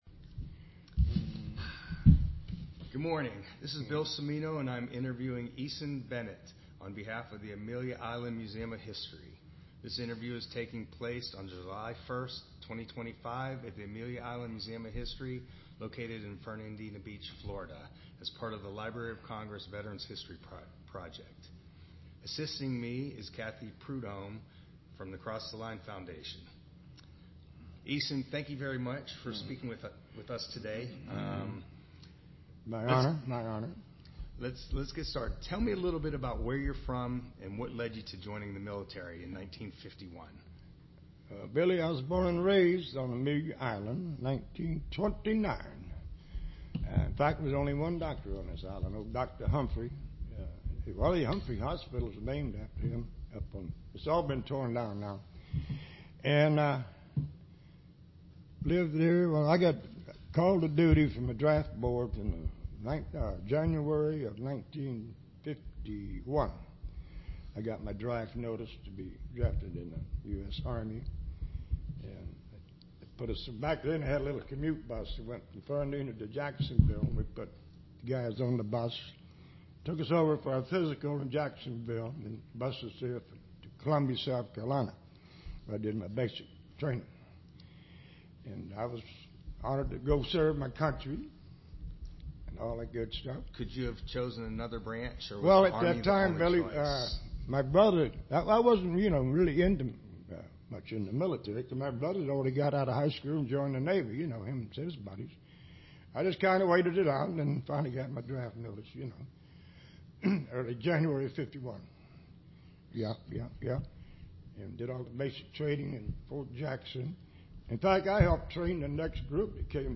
Click here to view/hear part one of the Oral History file.